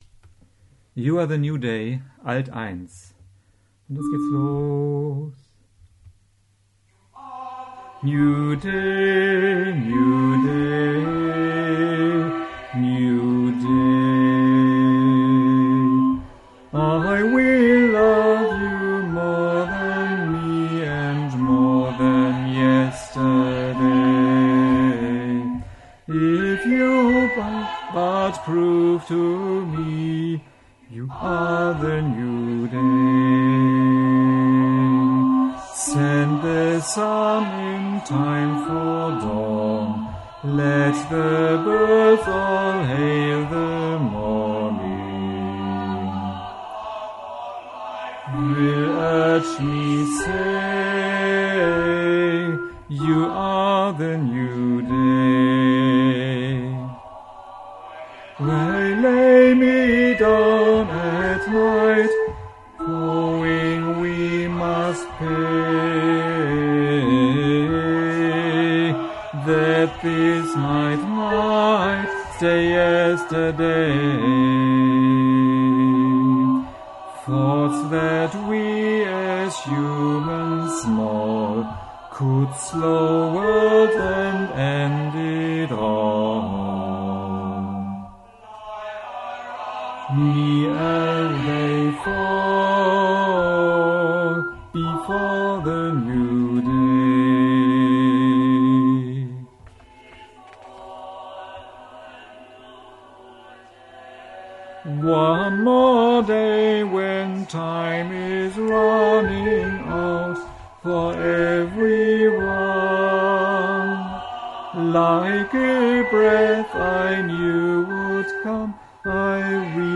You Are The New Day SATB – The Good News Singers